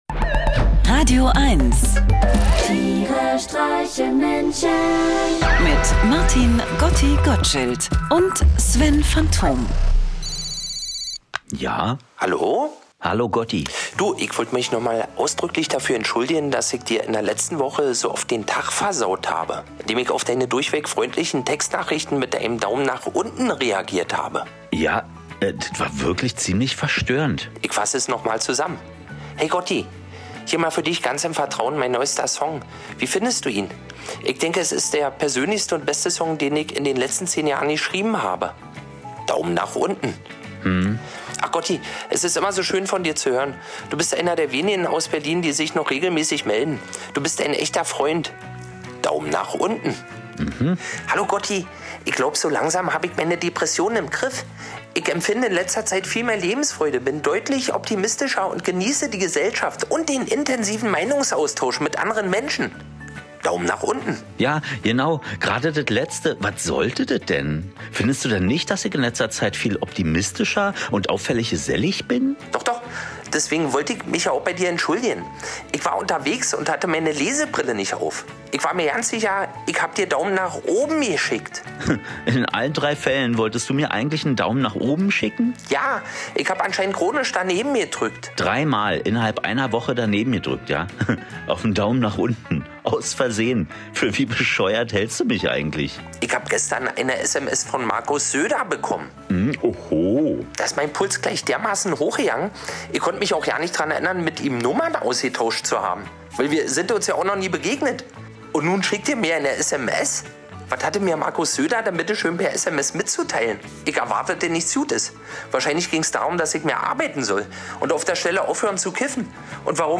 Comedy
aberwitzigem Humor gespickte Actionlesung. Einer liest, einer singt